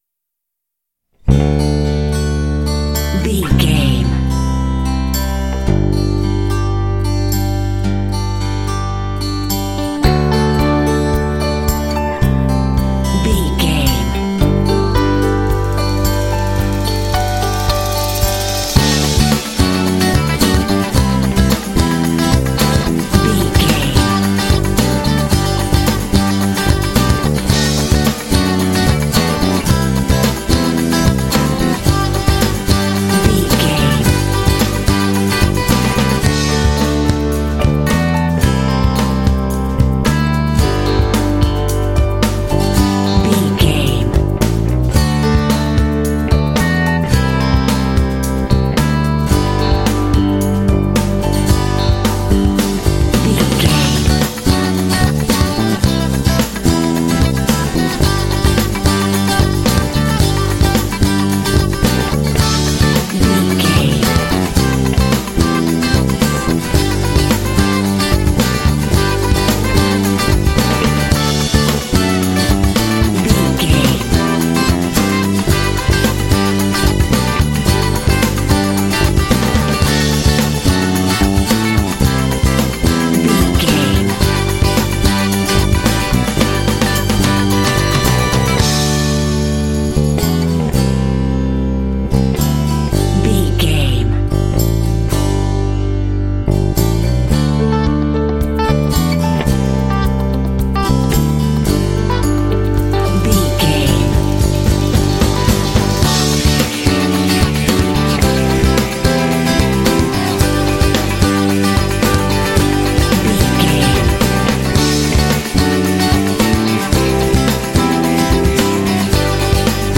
Uplifting
Ionian/Major
optimistic
happy
bright
acoustic guitar
electric guitar
bass guitar
drums
piano
percussion
pop
rock
indie